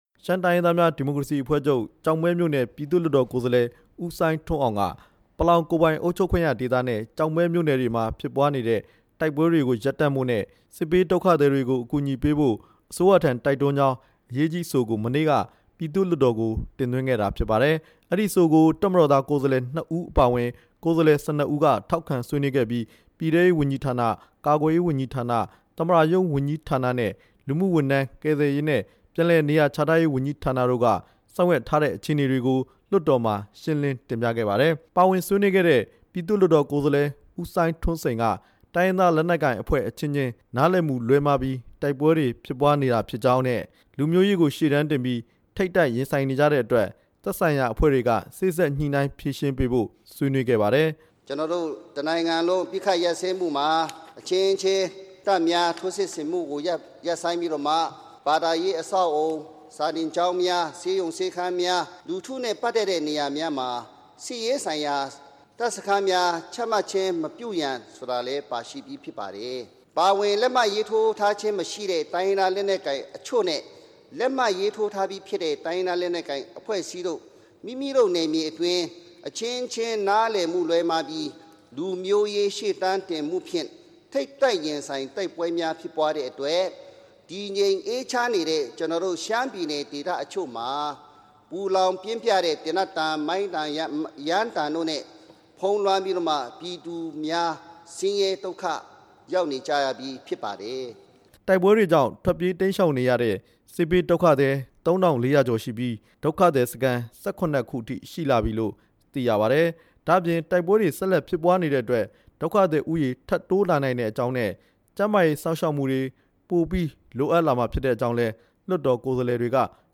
လွှတ်တော်ကိုယ်စားလှယ် ၁၂ ဦးနဲ့ သက်ဆိုင်ရာဝန်ကြီးဌာန တာဝန်ရှိသူတွေရဲ့ ပြောကြားချက်တချို့ကို